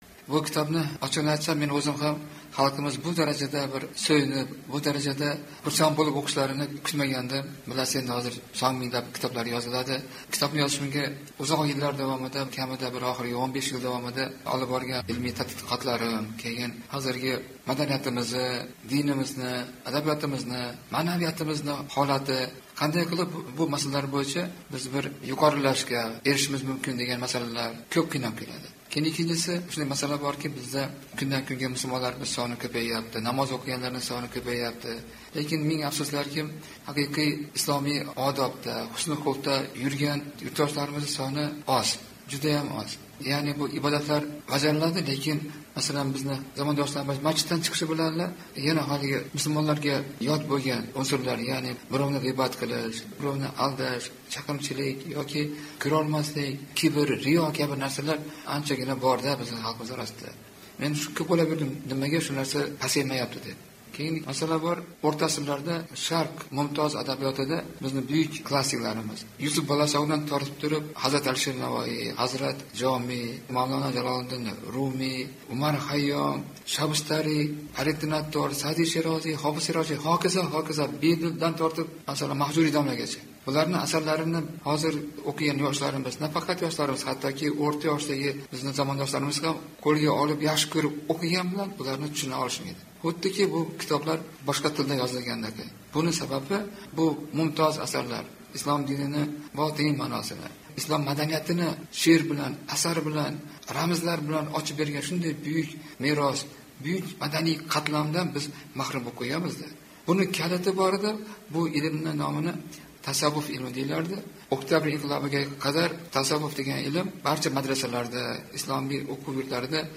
suhbat